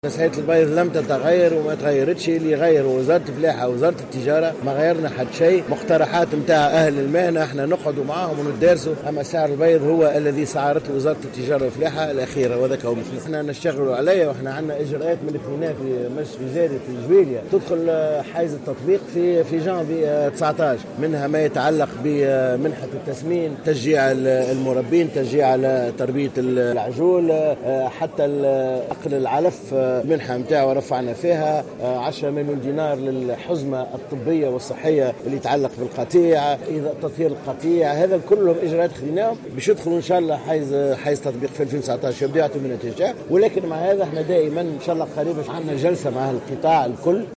أكد وزير الفلاحة في تصريح للجوهرة 'اف ام" اليوم الإثنين 17 ديسمبر على هامش زيارة يؤديها إلى ولاية سوسة في إطار المهرجان الدولي للزيتونة إن أسعار البيض لم تتغير ولم تتجاوز 840 مليما الذي حددته وزارة الفلاحة.